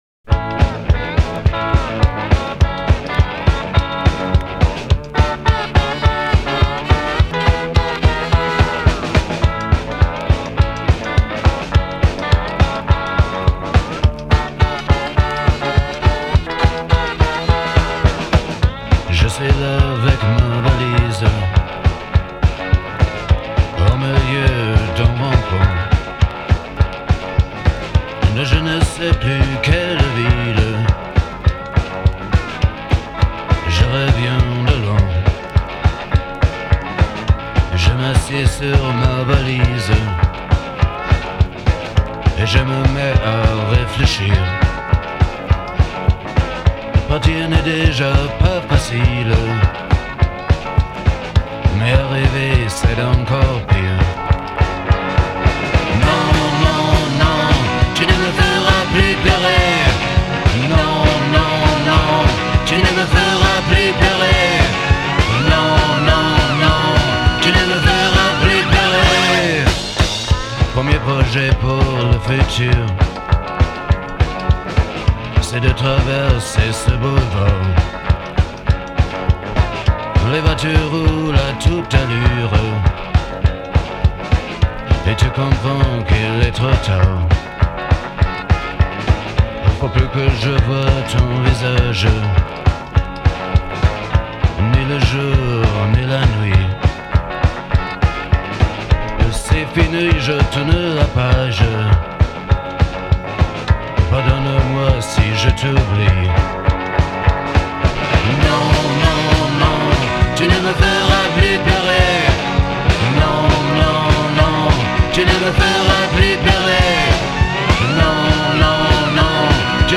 Rétro-décalé-un peu ça, quoi.